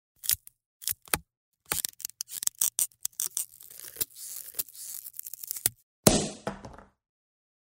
Звук открывания шампанского и вылетающей пробки